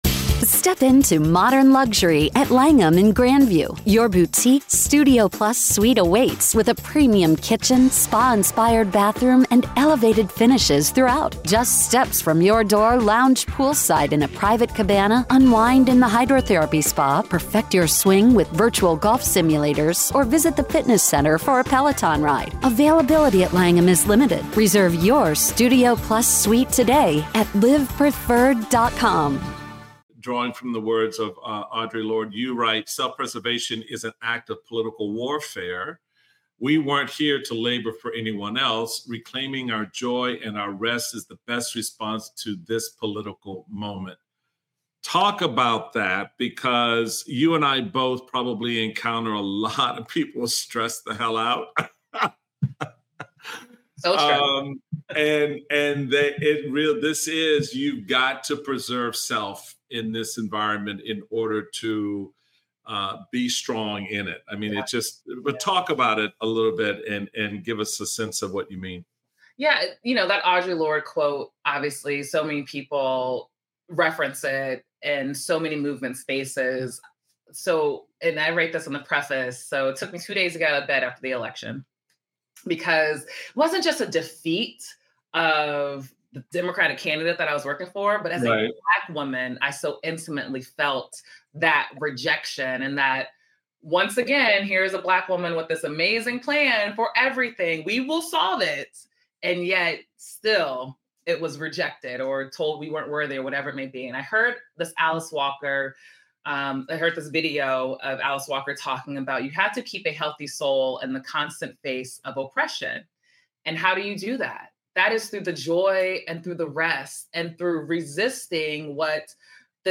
speaks with Michael Steele about how rest and self-preservation can be an act of resistance in today's political climate.